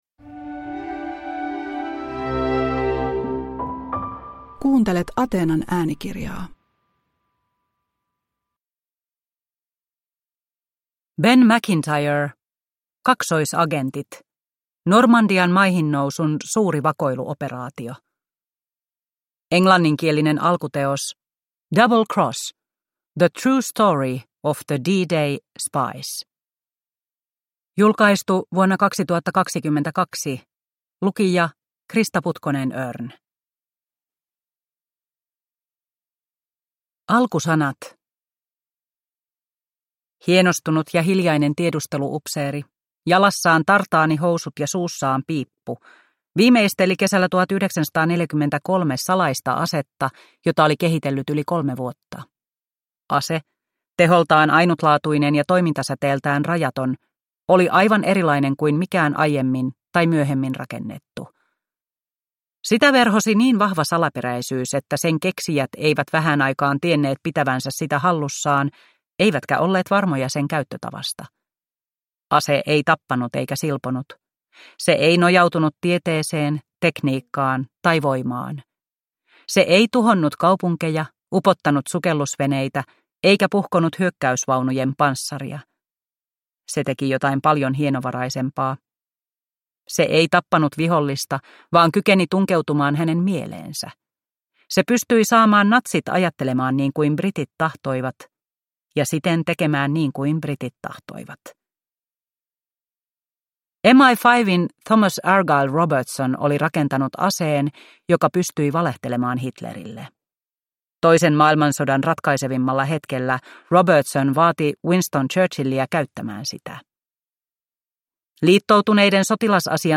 Kaksoisagentit – Ljudbok – Laddas ner